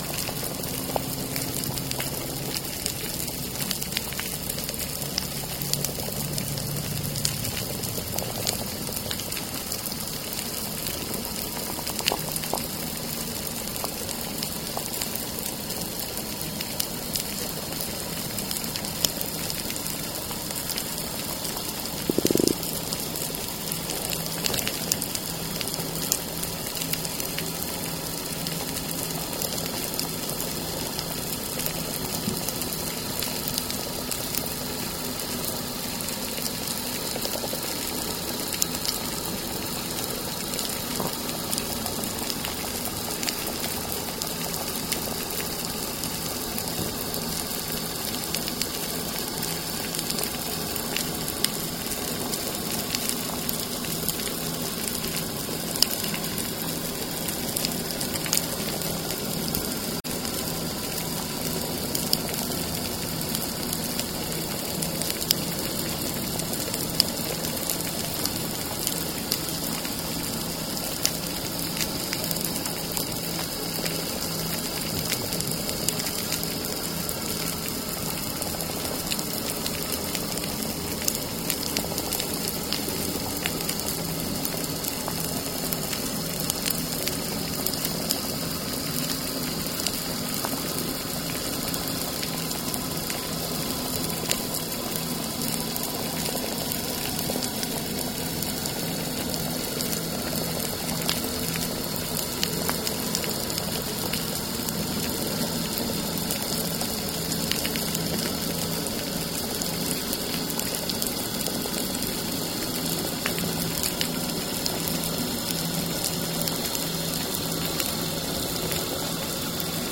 Demonstration soundscapes
anthropophony
biophony